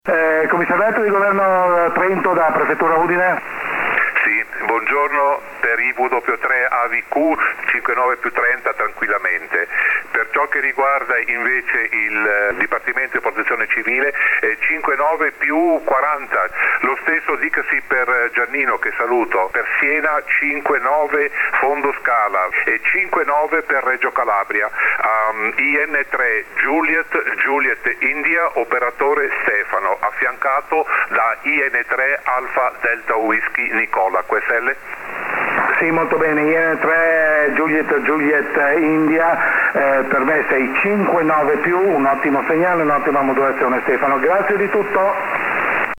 Per ascoltare il collegamento con la Prefettura di Udine che in questo caso fungeva da capomaglia, clicca  sull'immagine a fianco